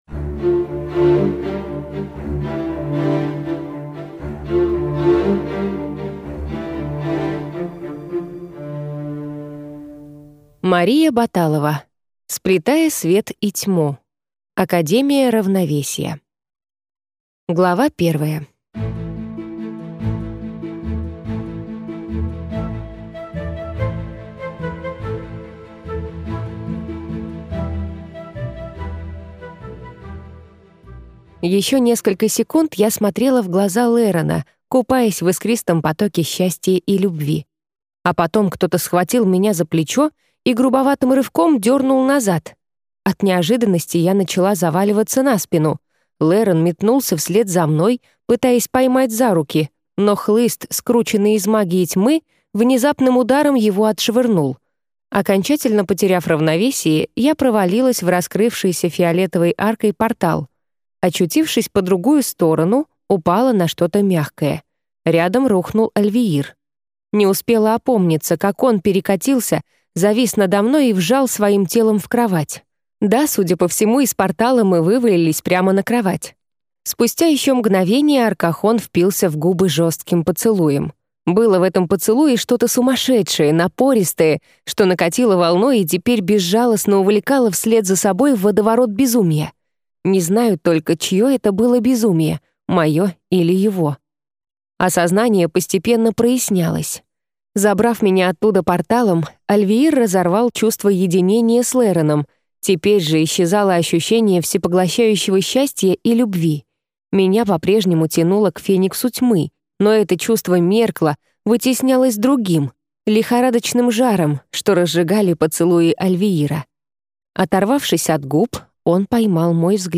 Аудиокнига Академия Равновесия. Сплетая свет и тьму - купить, скачать и слушать онлайн | КнигоПоиск